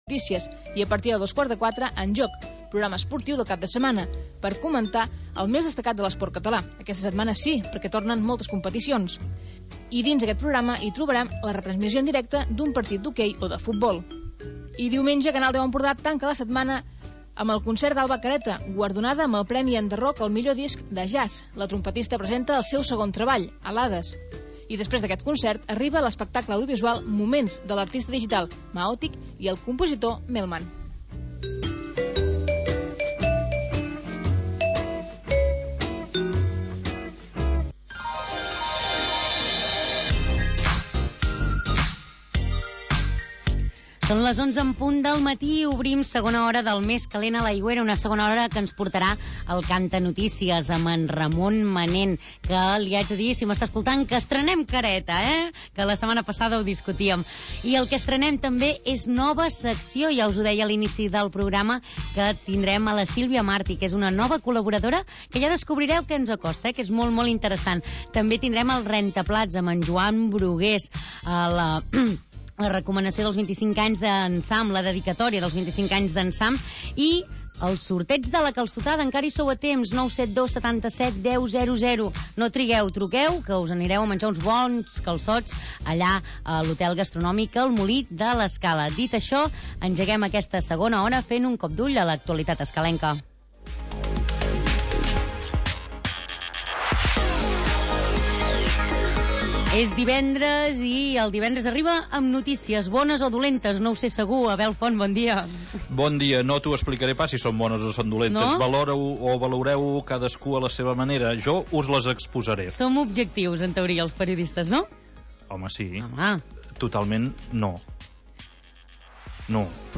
Magazin local d'entreteniment